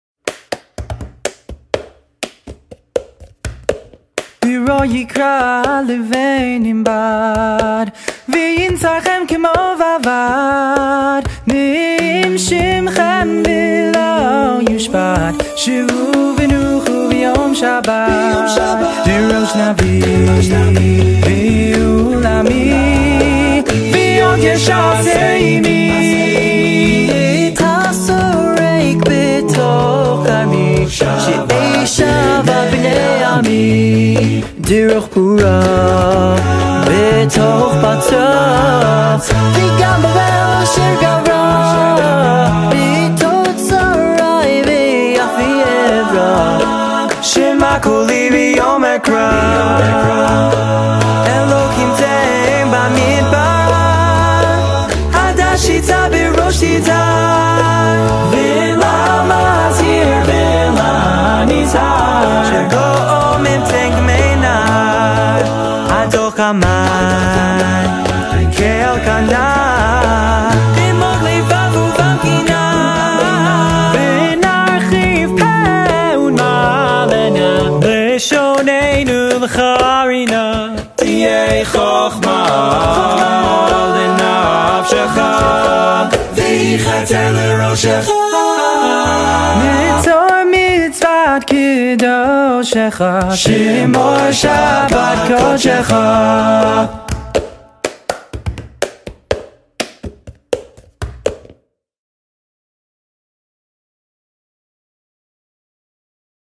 שירי אקפלה
כל הקולות מיוצרים ע" קולות מהפה.
זה עבודת צוות מטורפת הם בערך 10 גברים .